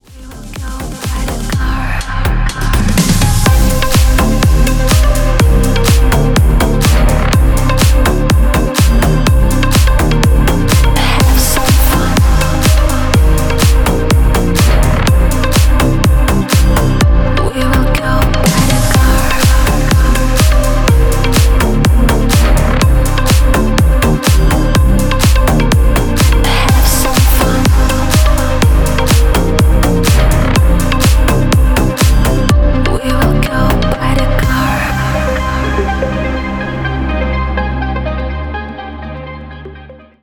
• Качество: 320, Stereo
громкие
мощные
deep house
мелодичные
чувственные